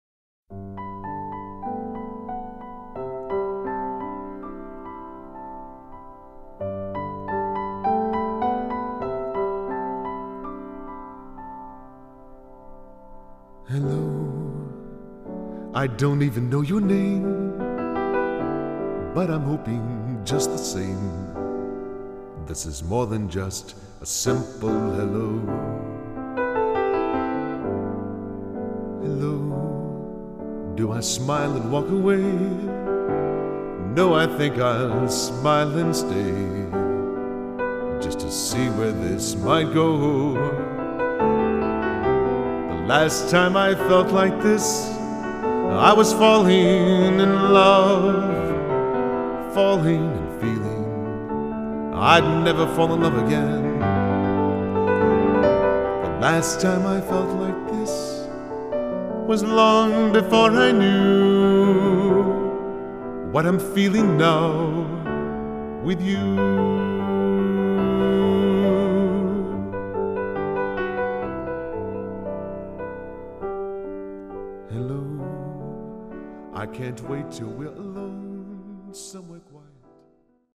piano/vocal